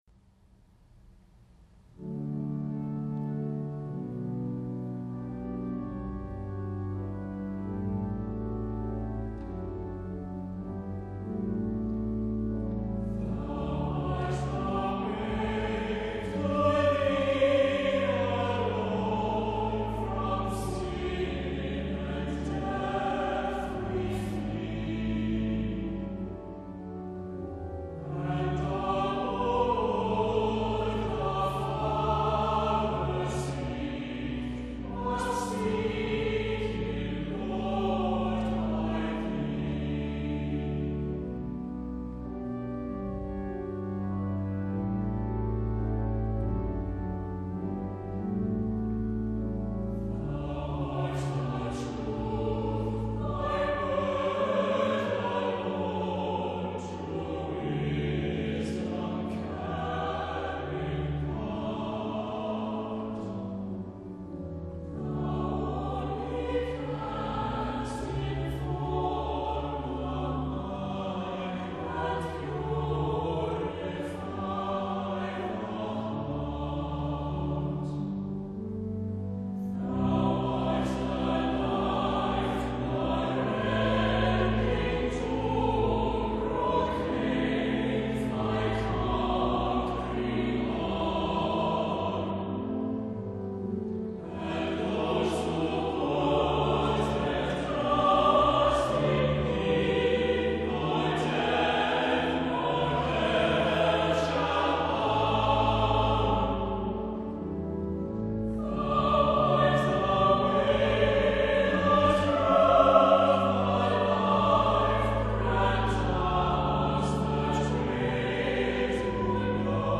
* singable melody and simple harmonies to suit the text